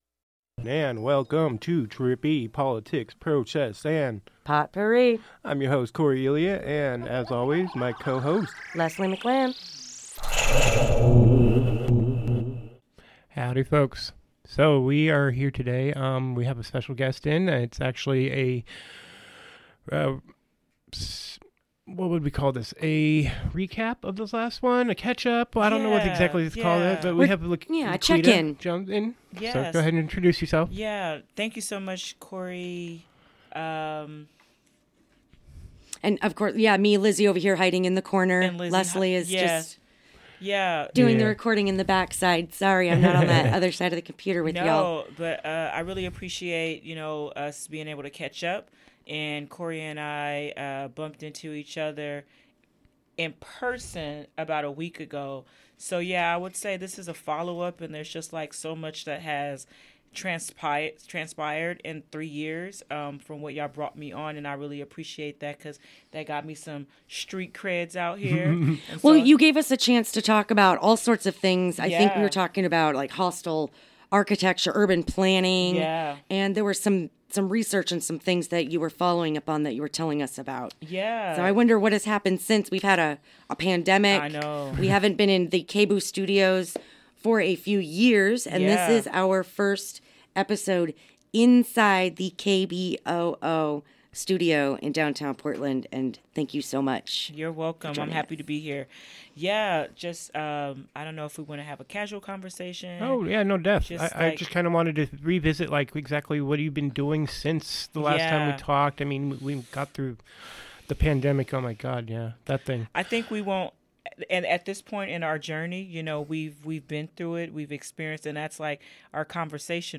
A discussion of the economic gentrification of Portland and an update on AfroVillagePDX.